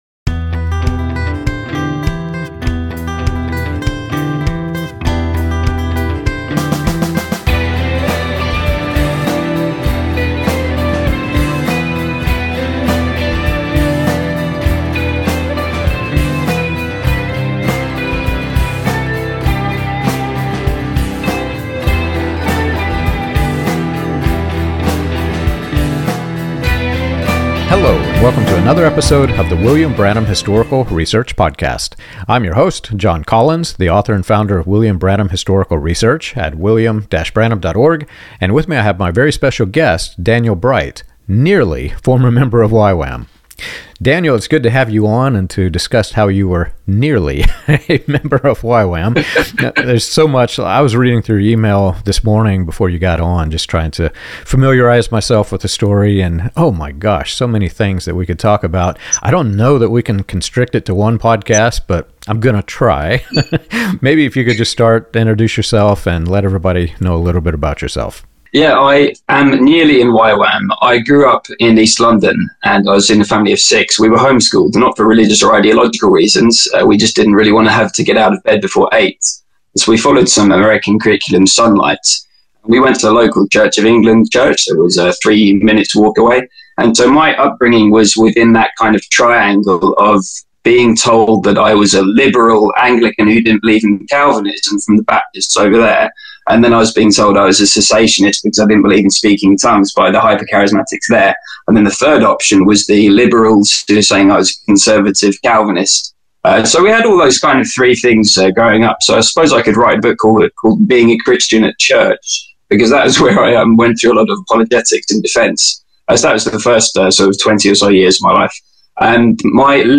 The conversation moves from charismatic history and linguistic authority to branding, cultural superiority, and the often-overlooked economic consequences facing former missionaries in their 30s.